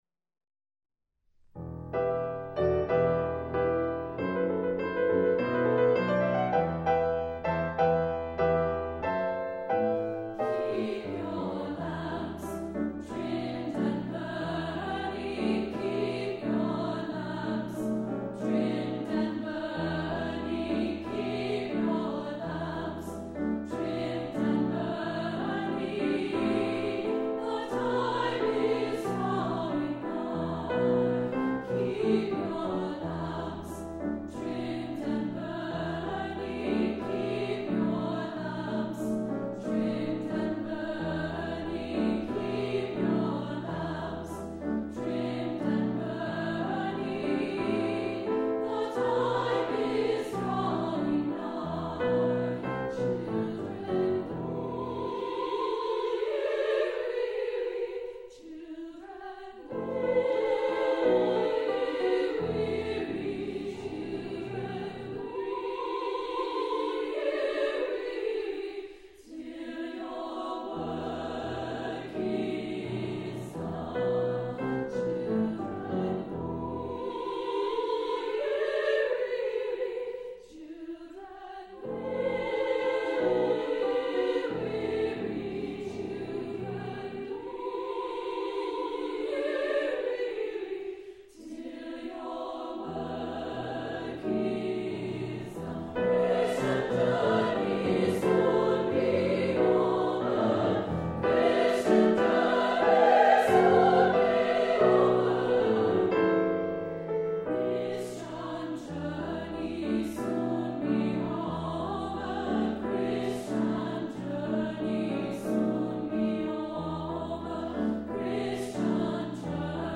Choral Spiritual Women's Chorus
Optional congas.
Traditional Spiritual
SSA